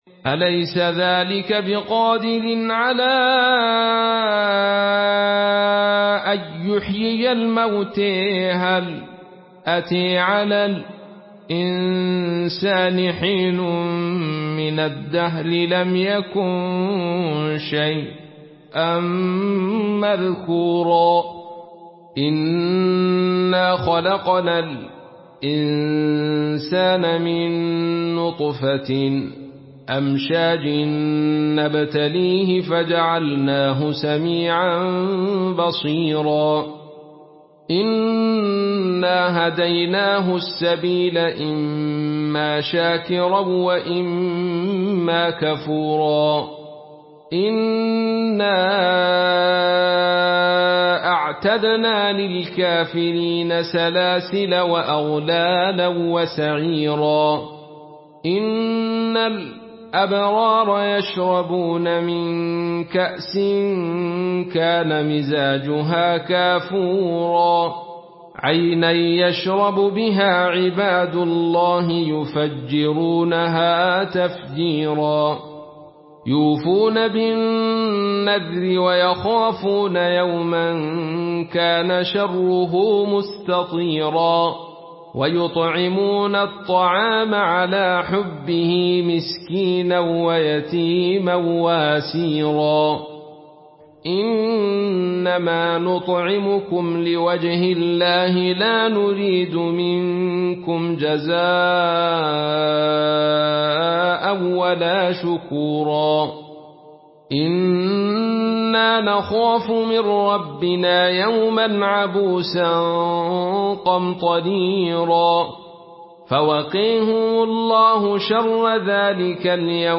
Une récitation touchante et belle des versets coraniques par la narration Khalaf An Hamza.
Murattal